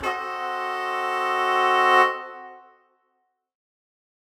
UC_HornSwell_Bdim.wav